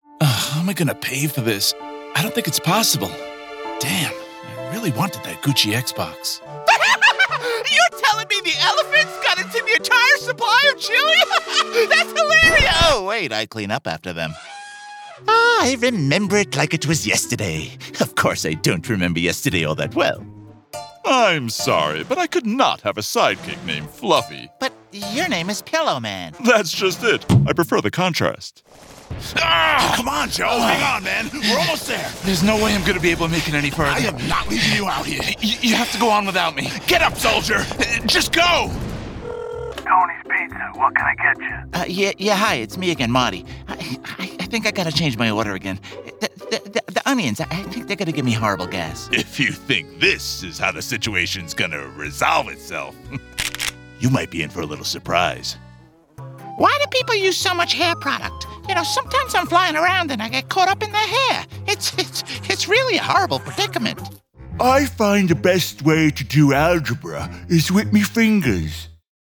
English (American)
Animation
BaritoneDeepLow
Character Voices